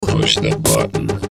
Download Push Button sound effect for free.
Push Button